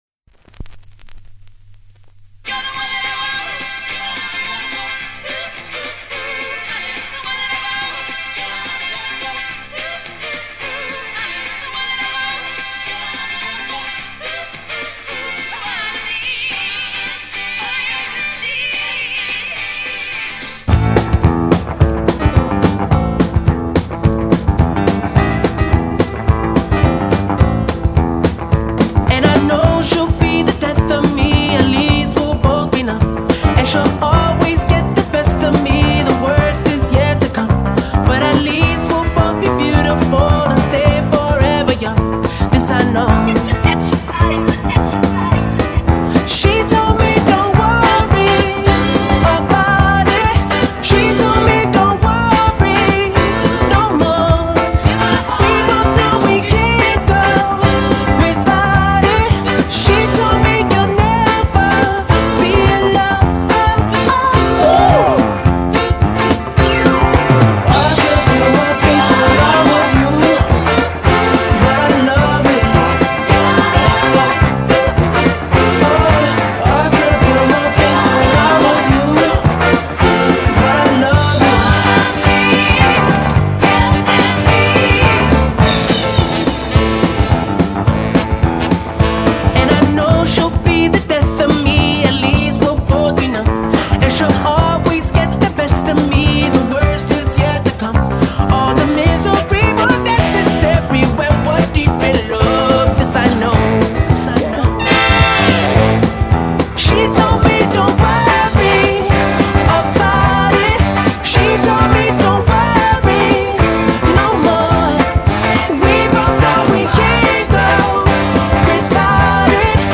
divertidísima mezcla